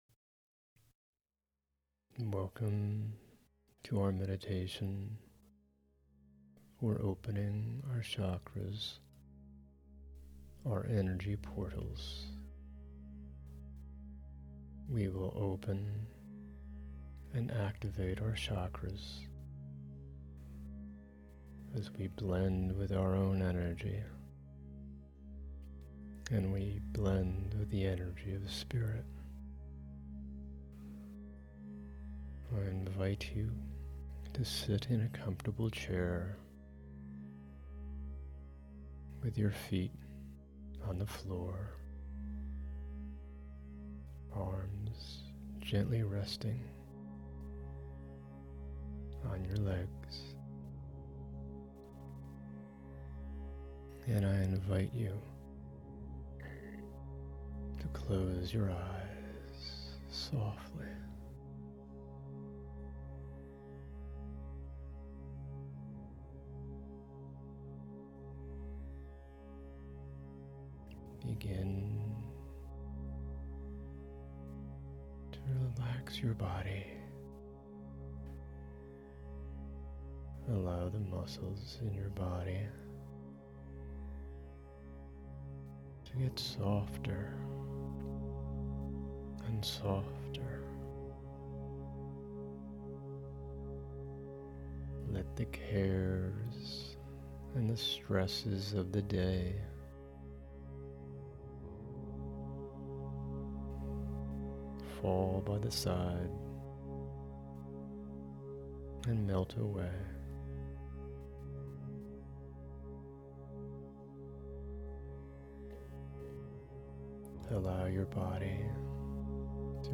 Chakra-Opening-Meditation.mp3